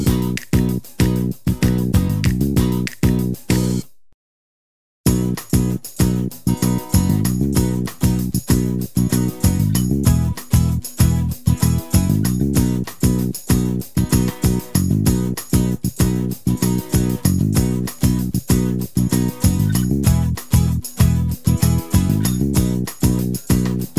Without Organ Intro Pop (1980s) 2:37 Buy £1.50